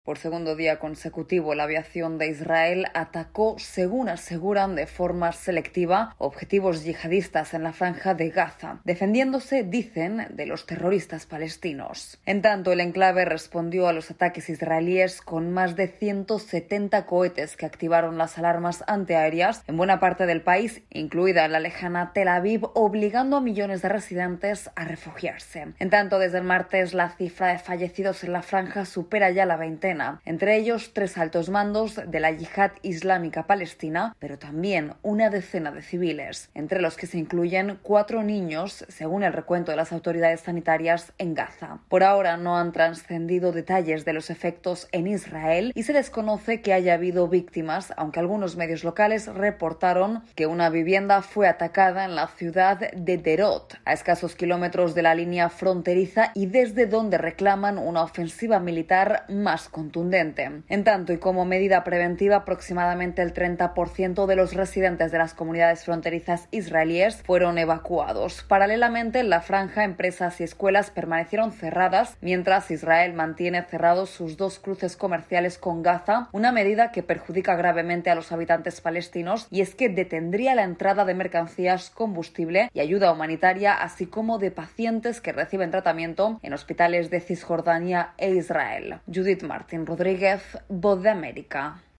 AudioNoticias
Retumban las sirenas antiaéreas en Tel Aviv ante un nuevo ataque con proyectiles desde la Franja de Gaza en respuesta a los últimos bombardeos israelíes sobre el territorio palestino.